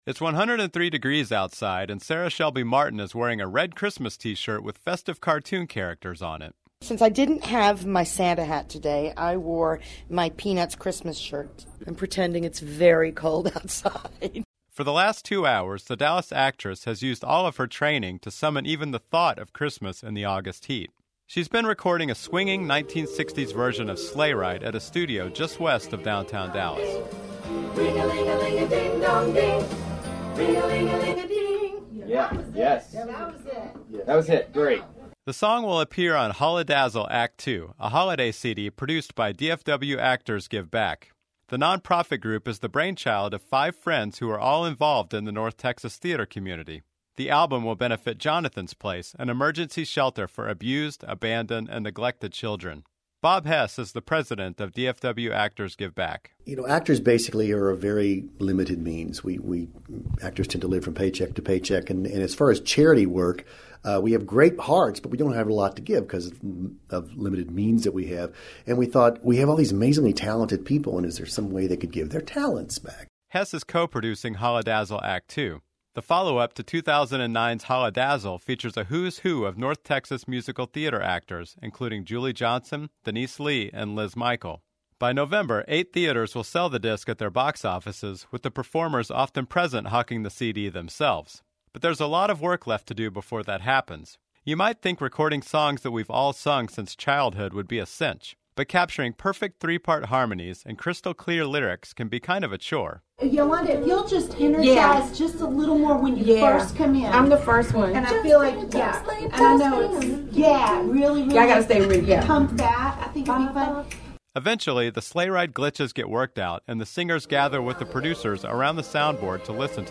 KERA radio story:
A big applause welcomes the last few notes.